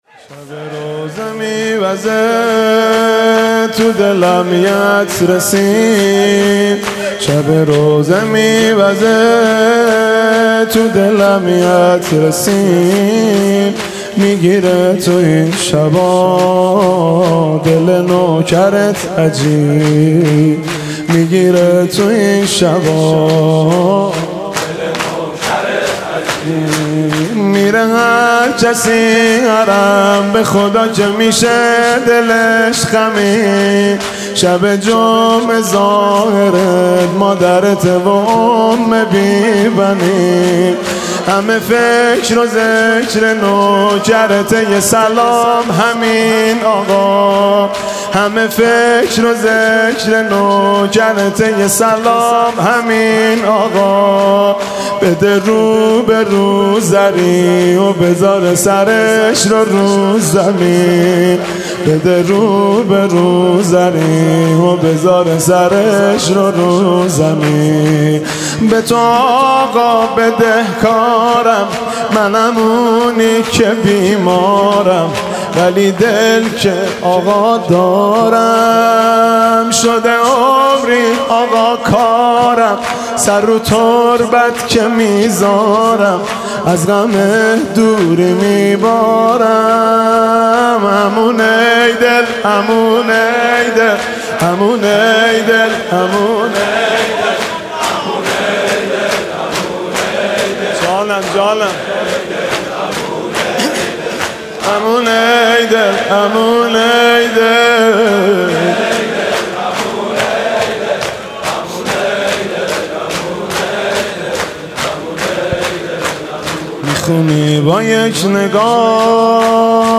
music-icon شور: شب جمعه زائرت مادرته و ام بی بنین حاج مهدی رسولی